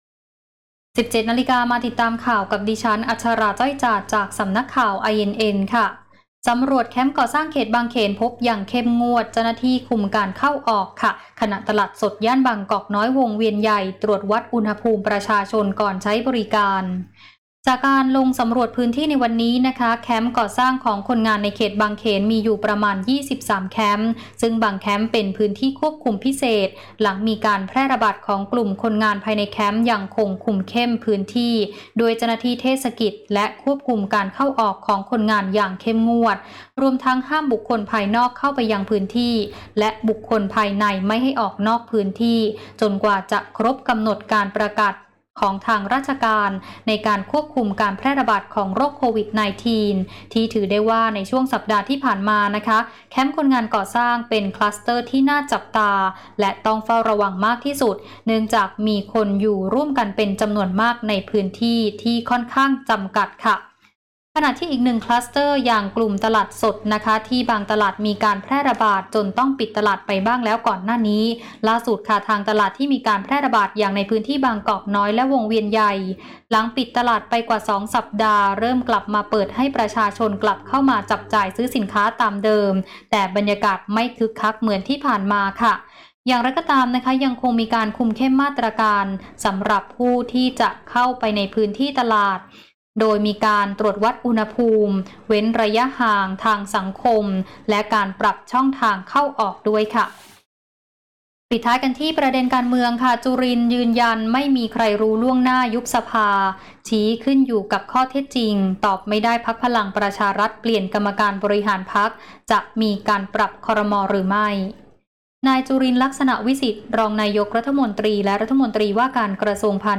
ข่าวต้นชั่วโมง 17.00 น.